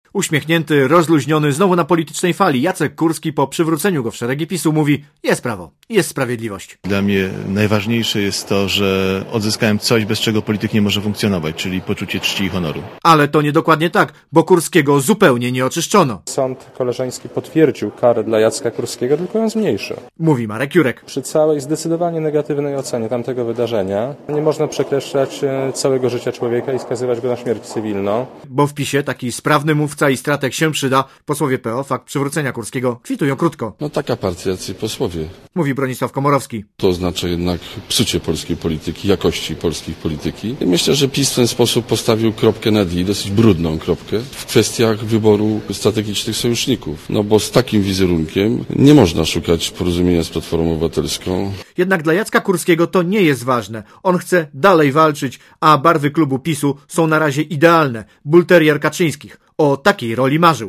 Źródło zdjęć: © PAP 15.11.2005 | aktual.: 15.11.2005 19:04 ZAPISZ UDOSTĘPNIJ SKOMENTUJ Relacja reportera Radia ZET